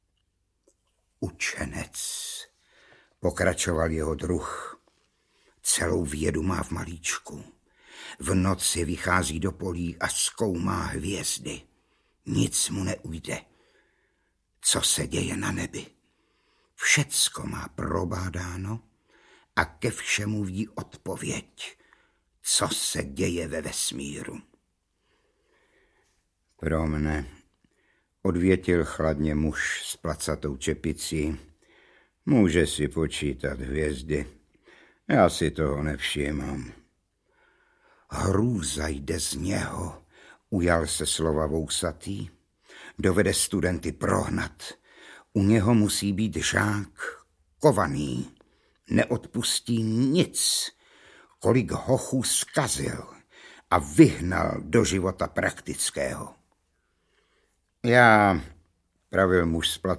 Audiobook
Read: Rudolf Hrušínský